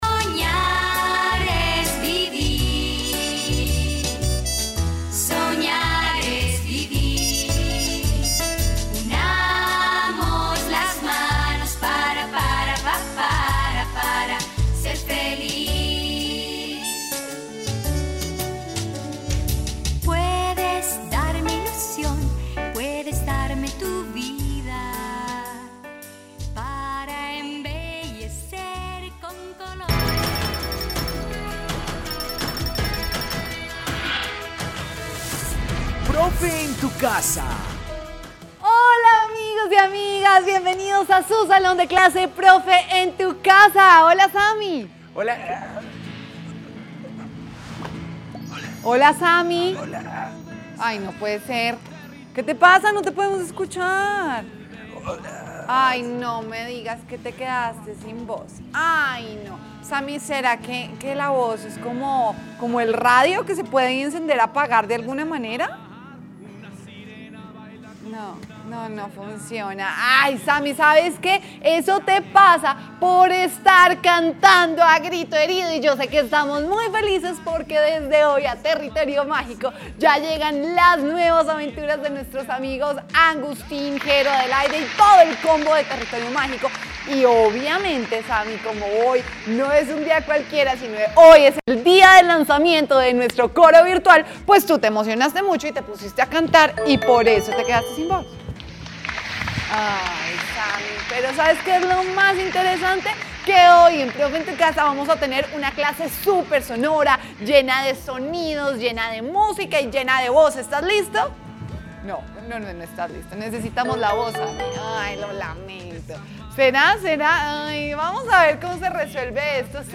La voz humana es mágica y compleja de entender. Únete a esta clase para que cantemos juntos y utilicemos nuestra voz para expresar nuestras emociones y sentimientos.